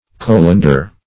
Colander \Col"an*der\, n. [L. colans, -antis, p. pr. of colare